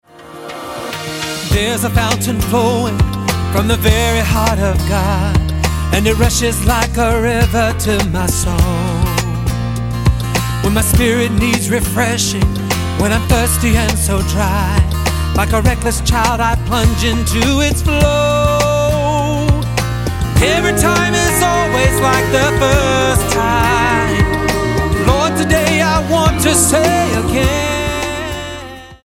STYLE: MOR / Soft Pop
straight forward pop